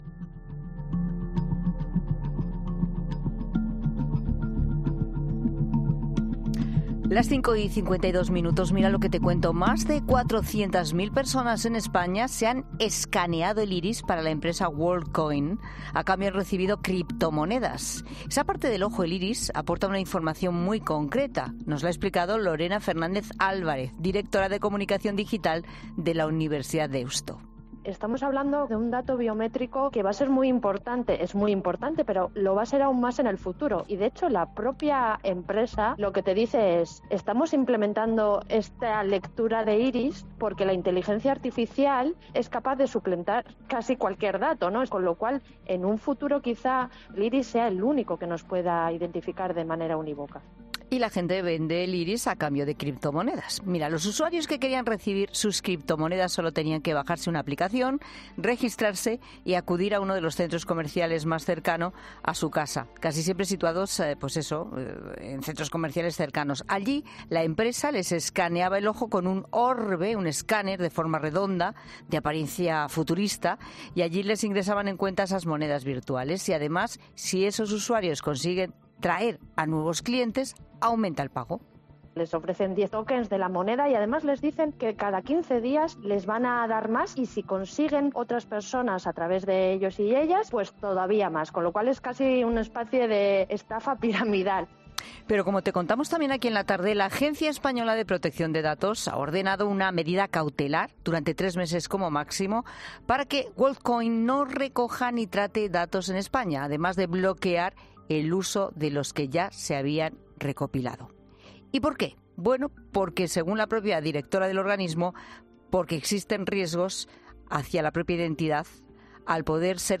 Una abogada experta en reclamaciones de consumidores explica en 'La Tarde' cómo te pueden devolver la información que han extraído de tu iris a cambio de criptomonedas